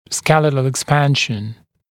[‘skelɪtl ɪk’spænʃn] [ek-][‘скелитл ик’спэншн] [эк-]скелетное расширние, расширение костного остова